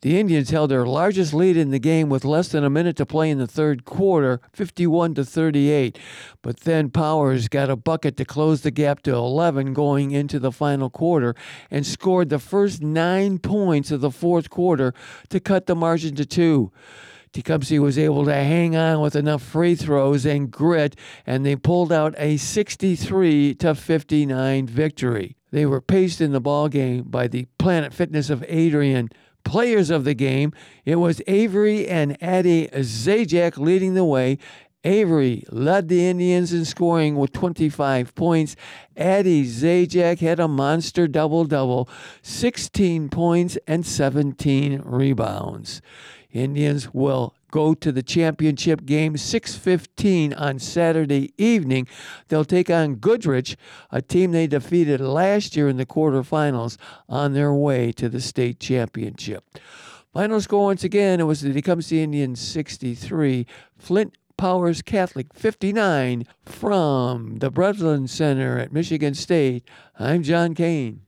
nws8521-aaa_sports_wrap.wav